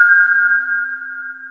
GLOCK LOOP.wav